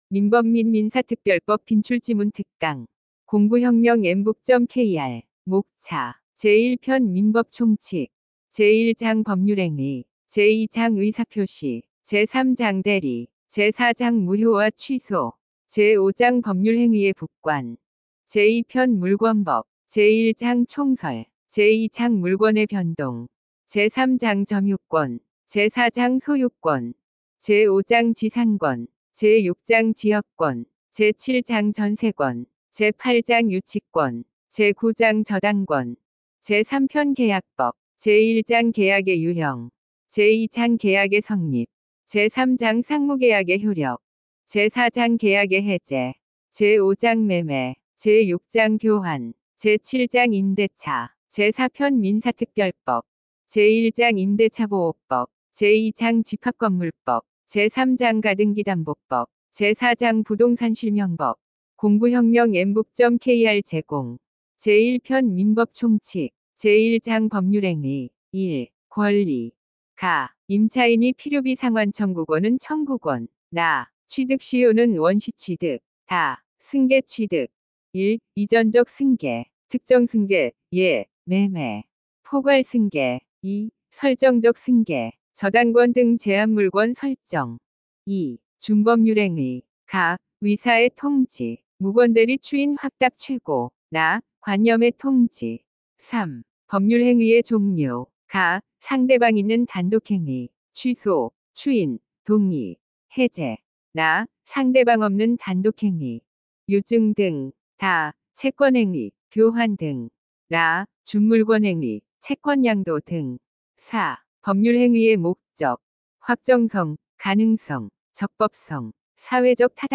▶ 강의 샘플(4분)